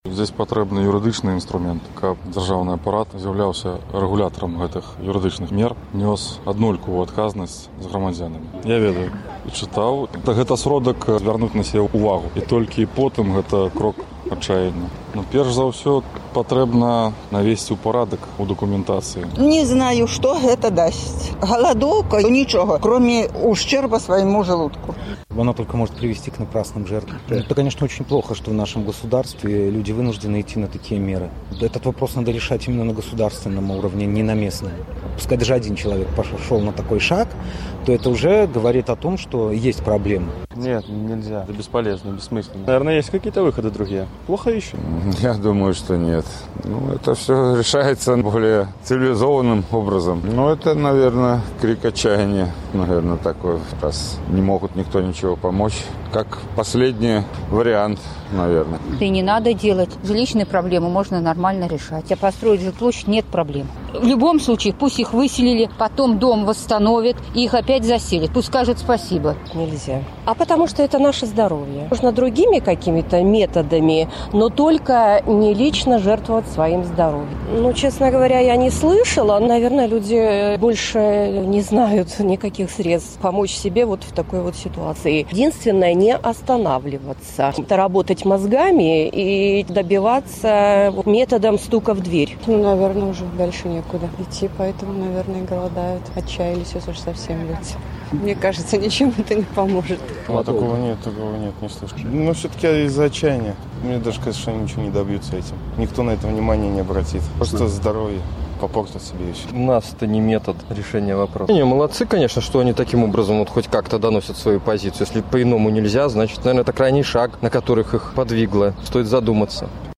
Vox populi
У сувязі з галадоўкай Свабода правяла апытаньне ў Магілёве: Ці можна ў Беларусі з дапамогай галадоўкі дамагчыся вырашэньня сваіх жыльлёвых праблемаў?